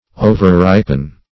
Overripen \O`ver*rip"en\, v. t.